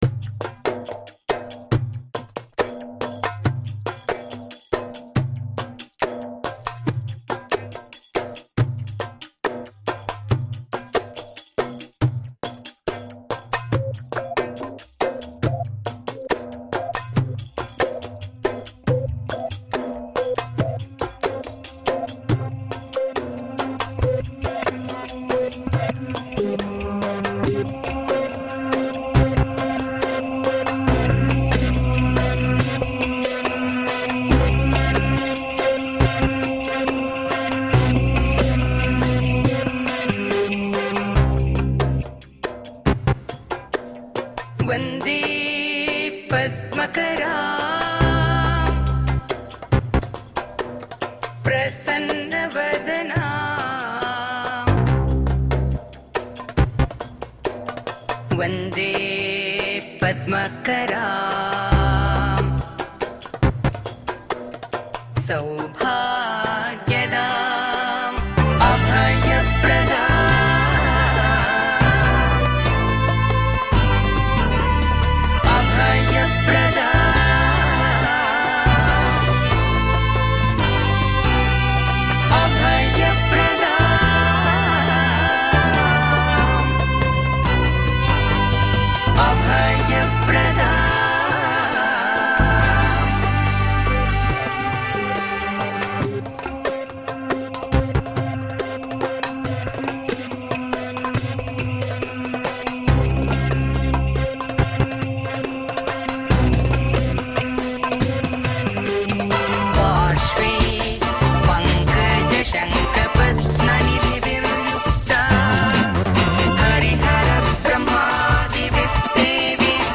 Vivid world-electronica grooves.